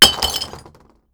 stone_shatter.wav